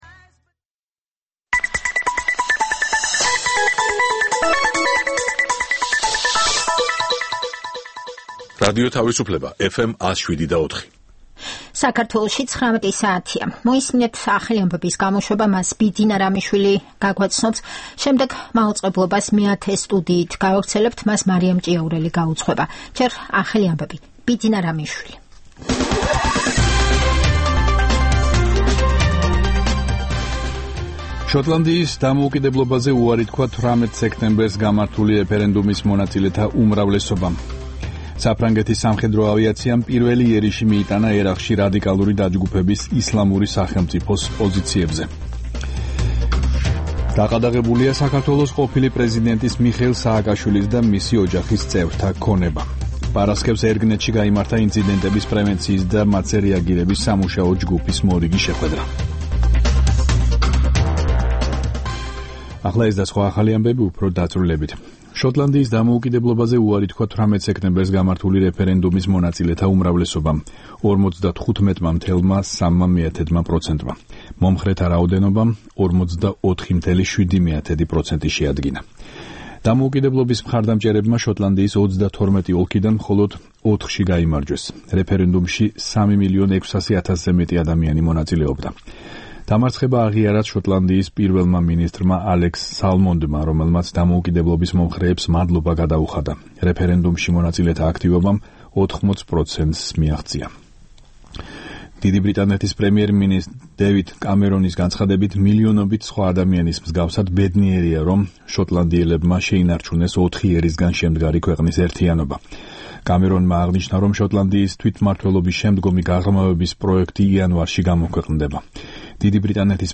ეს პროგრამა ჩვენი ტრადიციული რადიოჟურნალია, რომელიც ორი ათეული წლის წინათ შეიქმნა ჯერ კიდევ მიუნხენში - რადიო თავისუფლების ყოფილ შტაბ-ბინაში, სადაც ქართული რედაქციის გადაცემების ჩასაწერად მე-10 სტუდია იყო გამოყოფილი. რადიოჟურნალი „მეათე სტუდია“ მრავალფეროვან თემებს ეძღვნება - სიუჟეტებს პოლიტიკასა და ეკონომიკაზე, გამოფენებსა და ფესტივალებზე, ინტერვიუებს ქართველ და უცხოელ ექსპერტებთან და ხელოვანებთან.